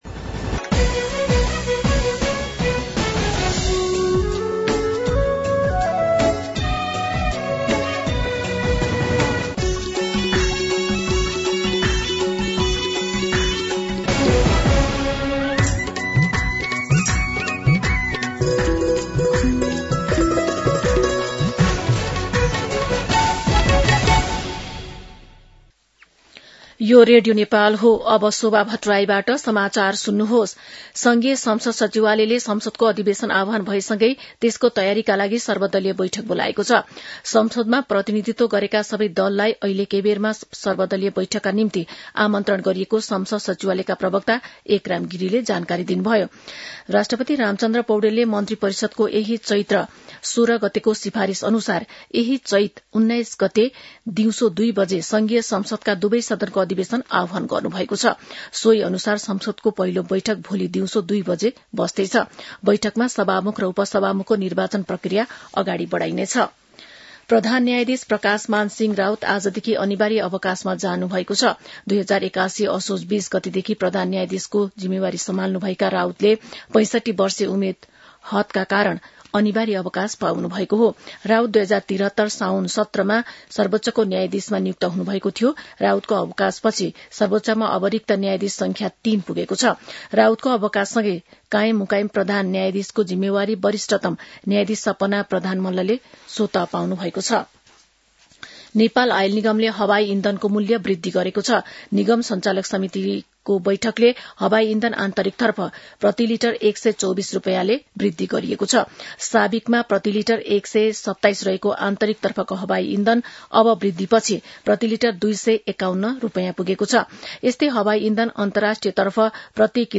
दिउँसो १ बजेको नेपाली समाचार : १८ चैत , २०८२
1pm-News-18.mp3